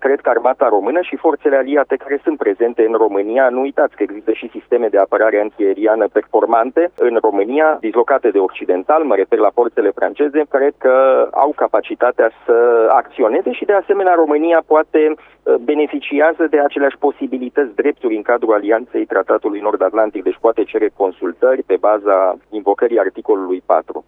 Analistul de politică externă